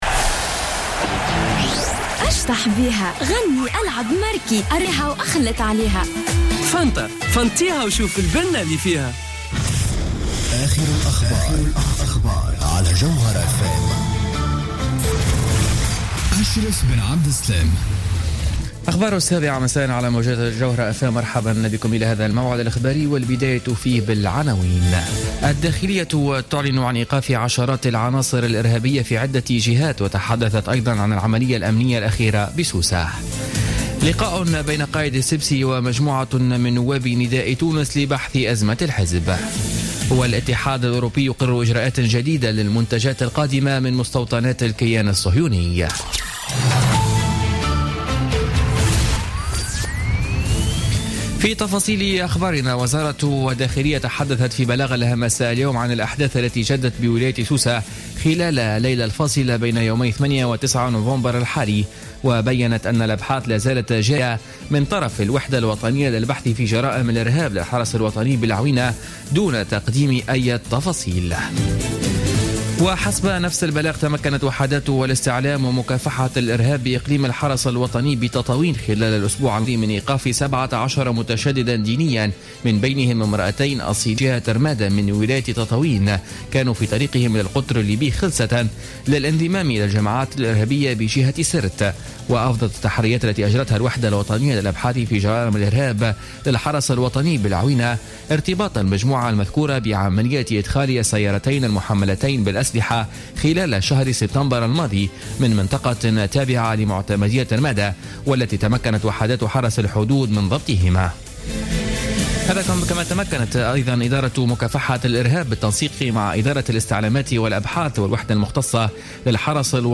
Journal Info 19h00 du mercredi 11 Novembre 2015